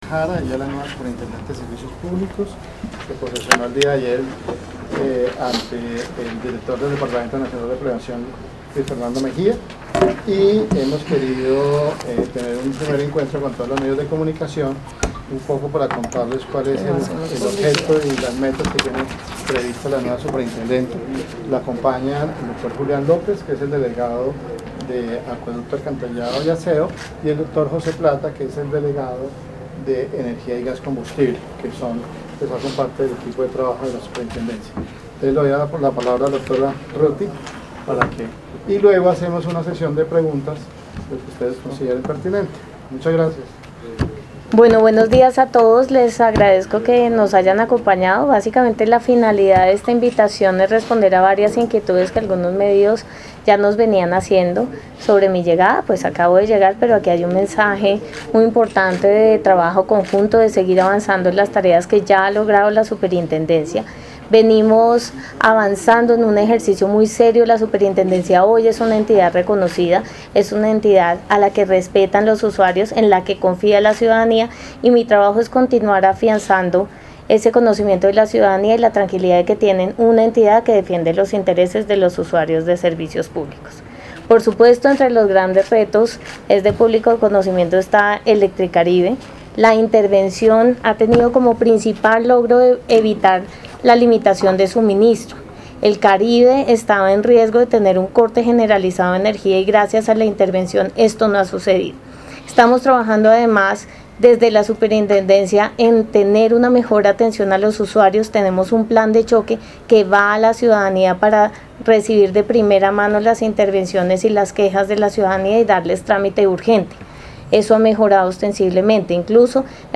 ruedadeprensa-superintendenteruttyortiz.mp3